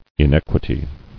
[in·eq·ui·ty]